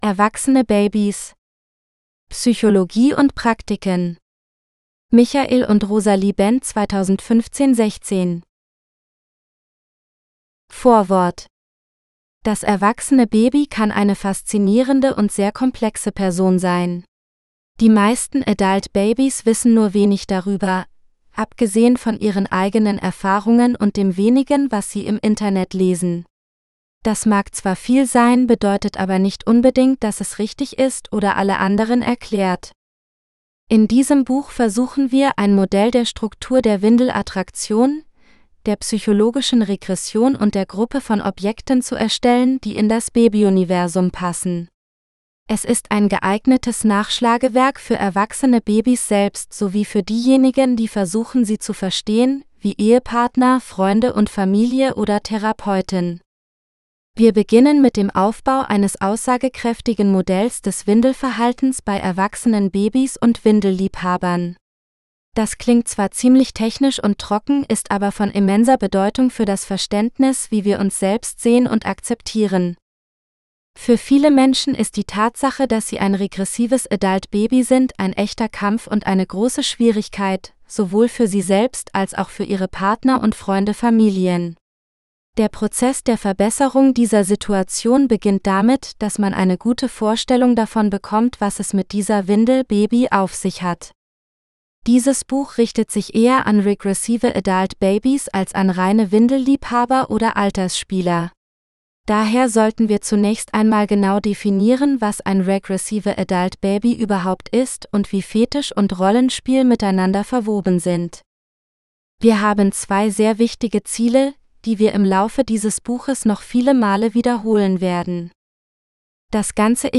AB Psychology GERMAN (AUDIOBOOK – female): $US15.90